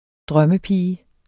Udtale